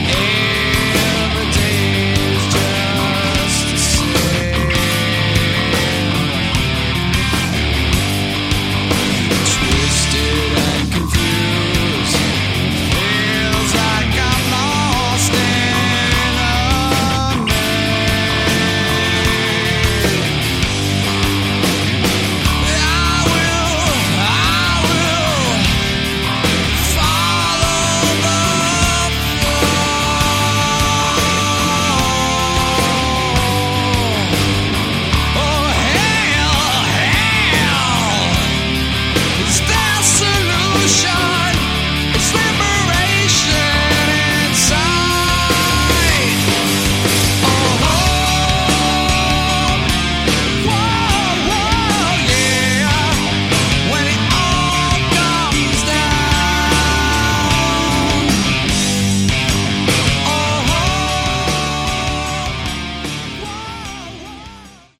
Category: Hard Rock
vocals
guitar, backing vocals
bass, backing vocals
drums